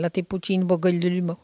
Patois - archive
Catégorie Locution